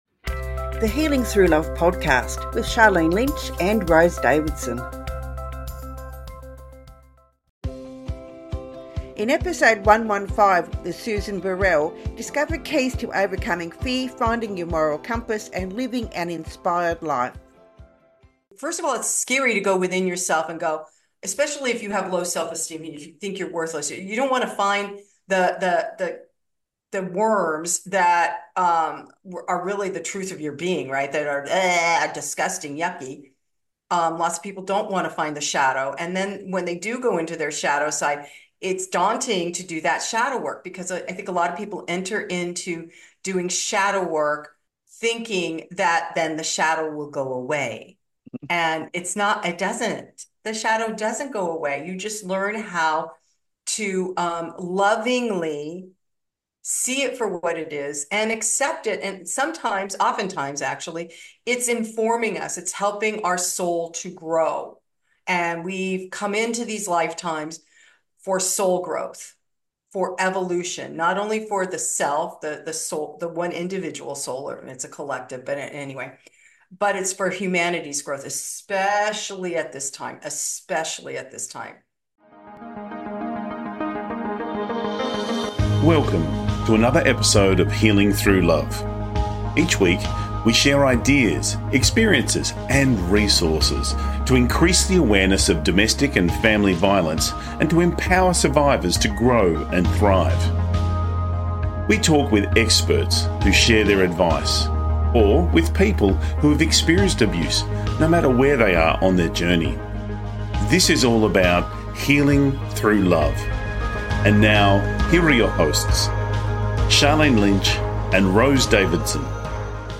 Key Points from the Interview: How guided meditation and mind mapping can help overcome self-doubt and build self-esteem. The importance of finding your moral compass to realign your life with purpose and values.